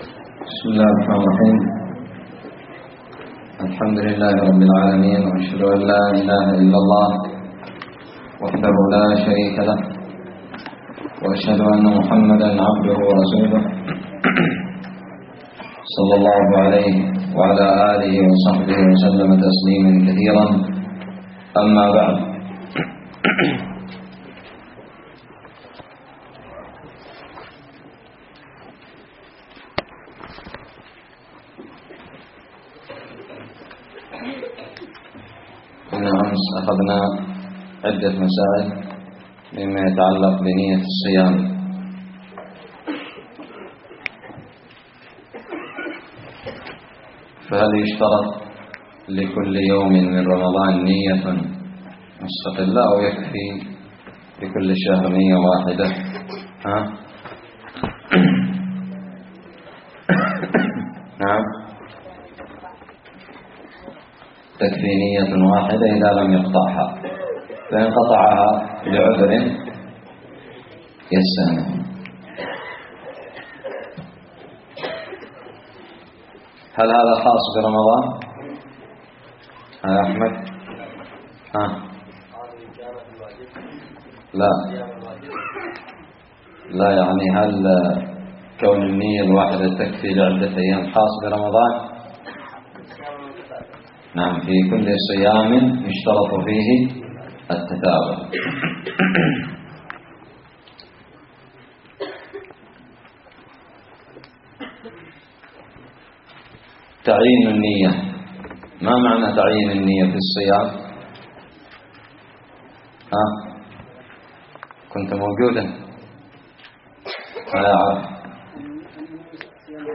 الدرس التاسع من كتاب الصيام من الدراري
ألقيت بدار الحديث السلفية للعلوم الشرعية بالضالع